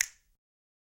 snap3.wav